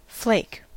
Ääntäminen
IPA : /ˈfleɪk/